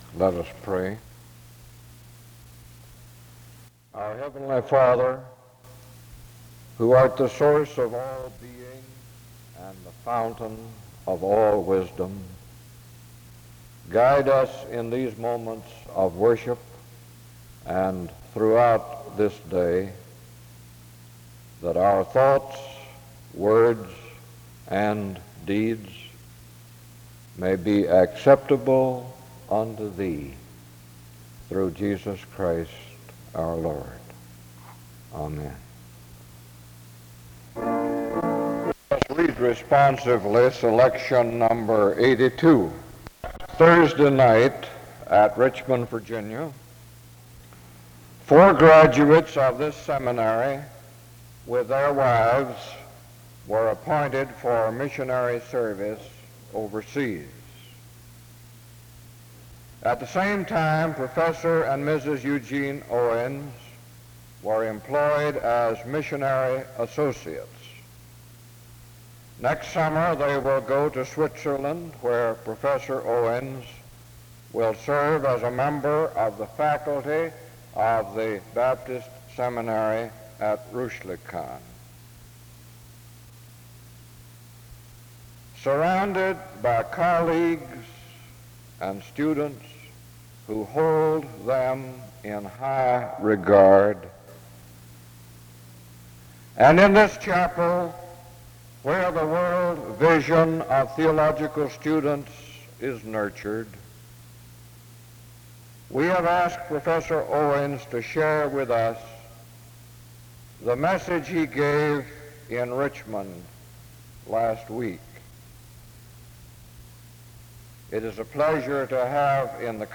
An opening prayer takes place from 0:00-0:35.